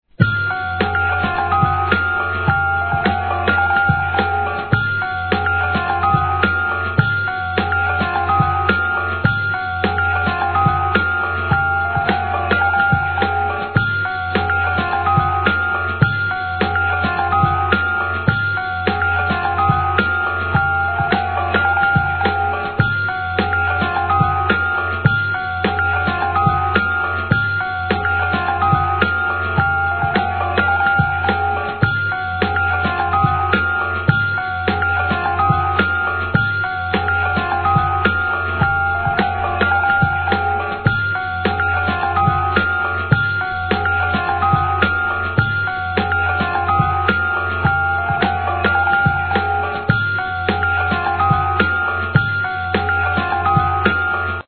HIP HOP/R&B
(106BPM)